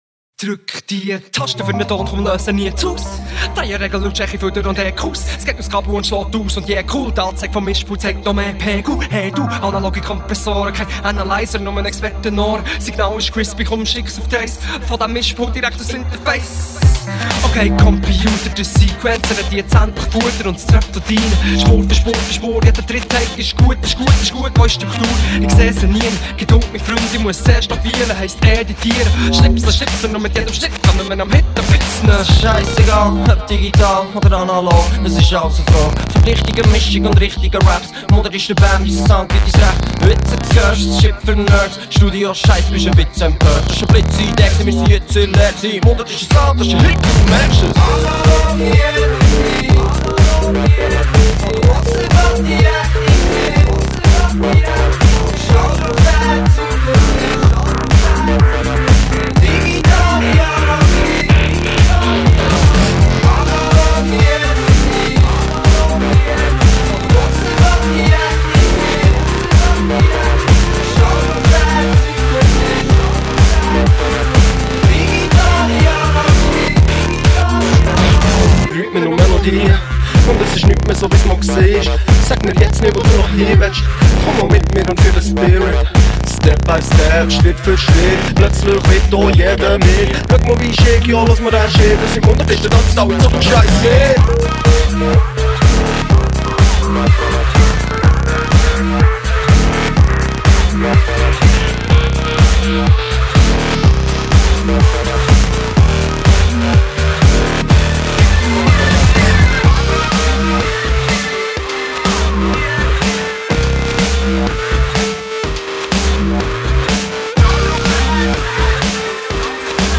Swiss-German dialect rap / pop.
garnishes rap with electronica elements and pop melodies.